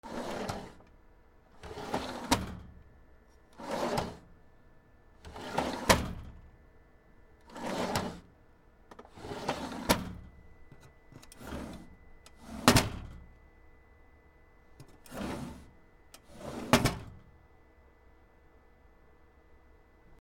/ K｜フォーリー(開閉) / K20 ｜収納などの扉